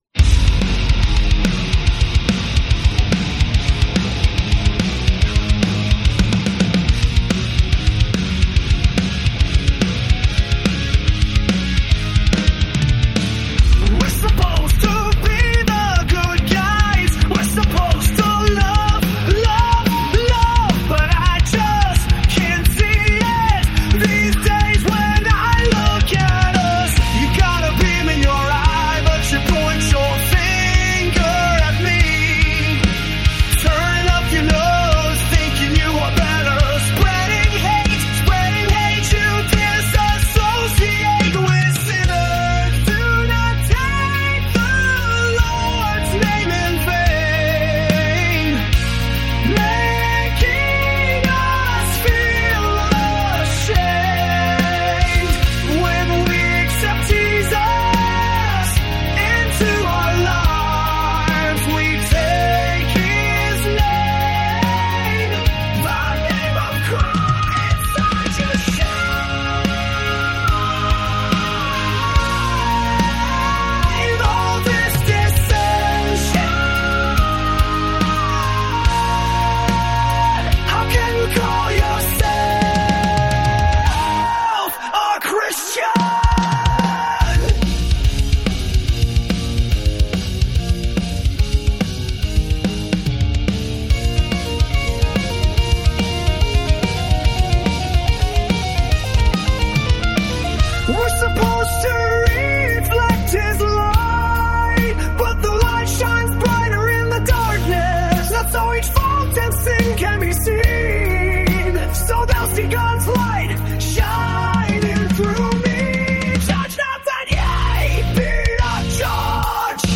your poem was inspiring and your voice captivating.
Christian metal